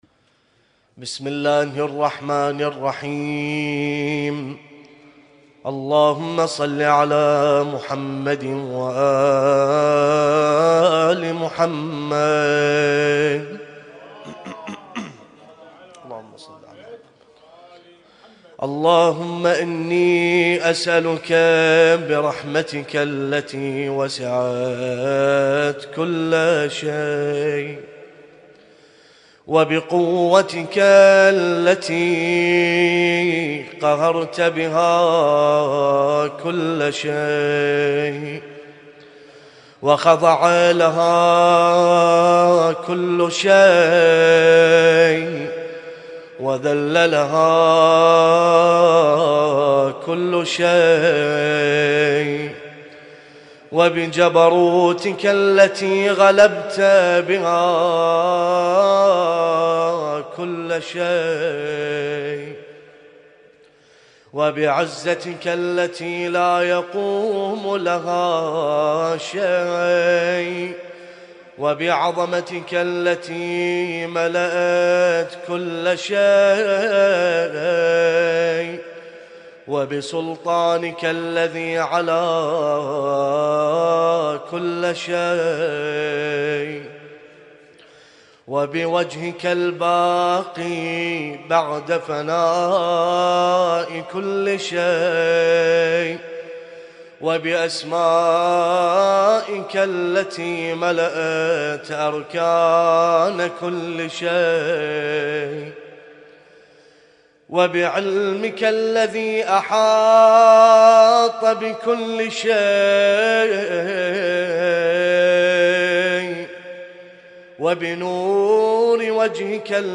اسم التصنيف: المـكتبة الصــوتيه >> الادعية >> دعاء كميل